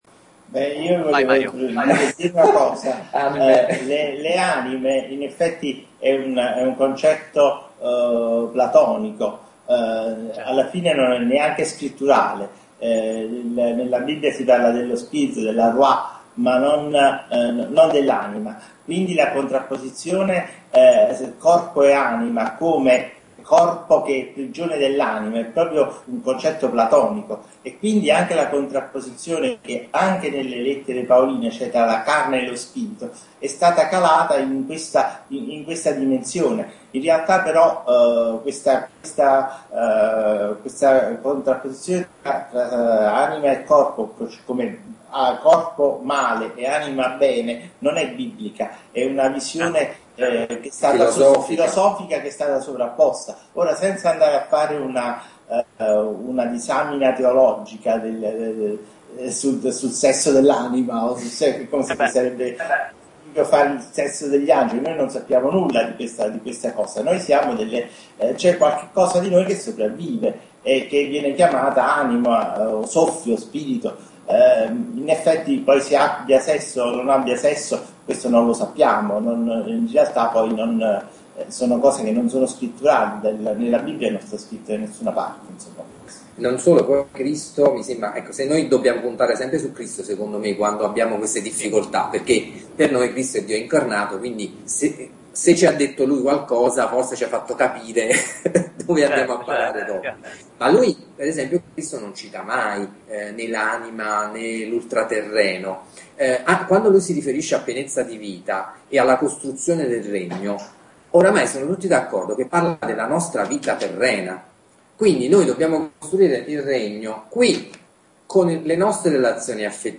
Conversazione